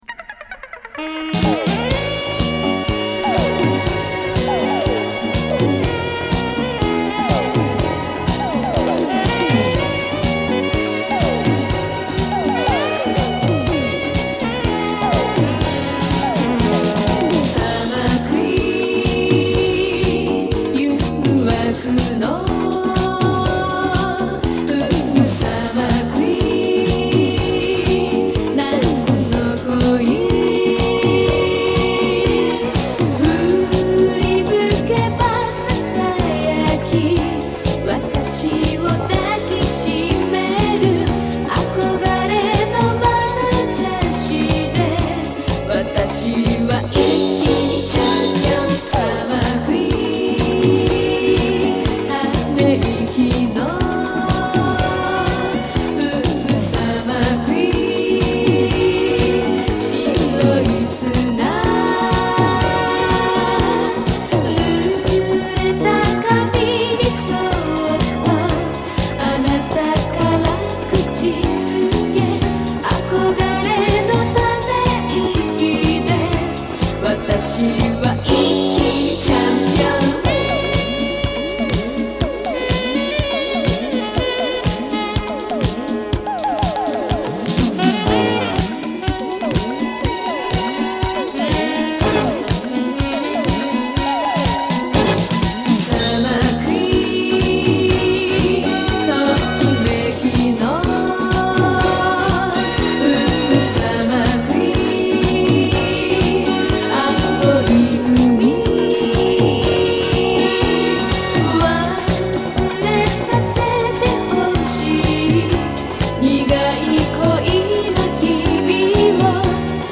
トロピカル和ディスコ